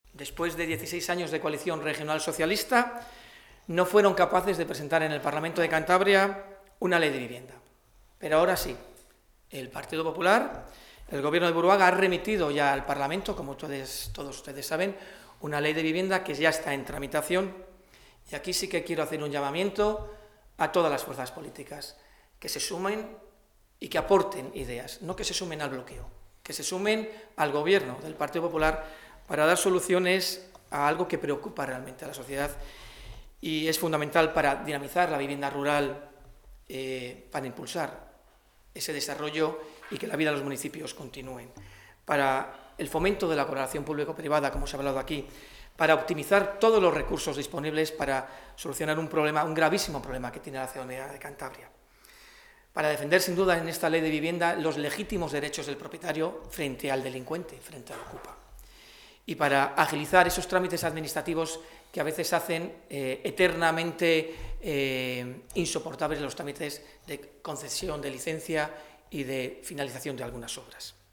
El diputado nacional, Félix de las Cuevas, ha presentado hoy en una rueda de prensa el Plan Integral de Vivienda de Feijóo, junto al coordinador del PP cántabro y portavoz parlamentario, Juan José Alonso, quien ha destacado las políticas que está impulsando en esta materia el Gobierno de Buruaga.